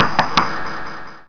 NMknock.wav